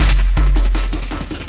53 LOOP 05-R.wav